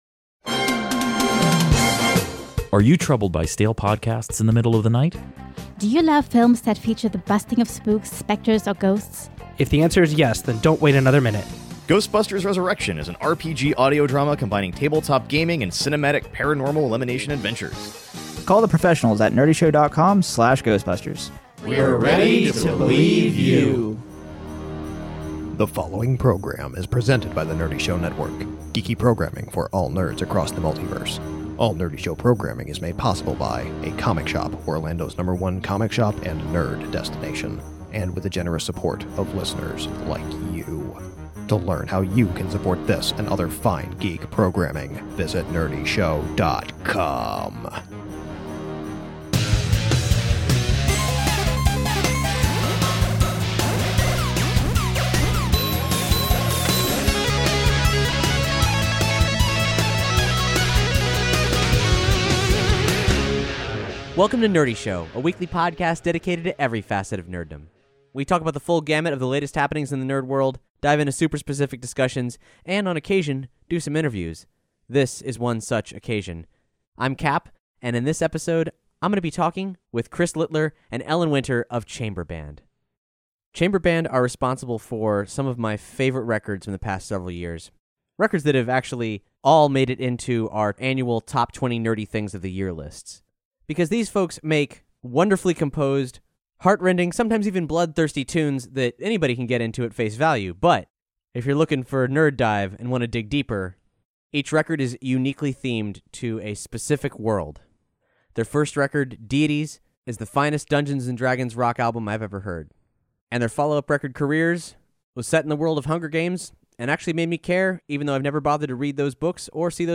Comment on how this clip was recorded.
Hear about how this musical/ audio drama hybrid came to be, the challenges of drastically changing up both formats, and getting props from the creator of Hamilton. We also debut a new track and unleash an unheard rarity from the archives.